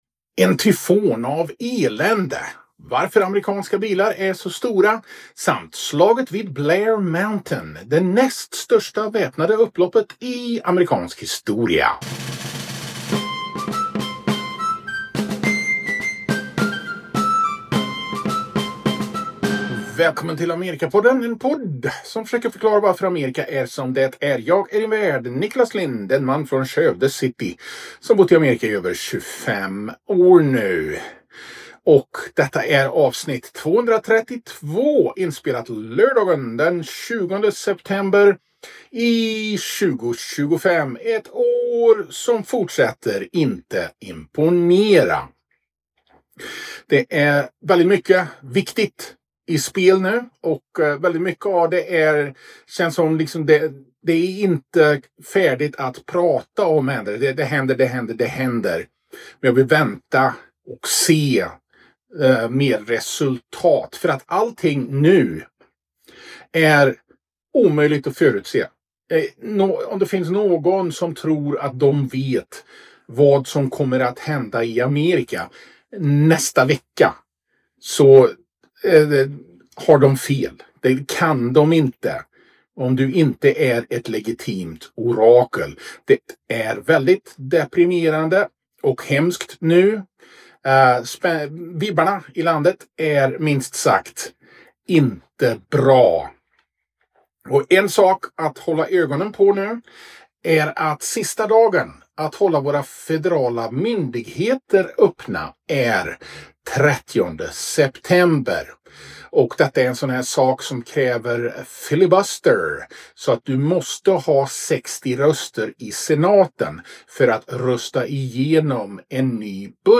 NOTERA: På grund av tekniska problem är ljudkvaliteten inte till vanlig standard.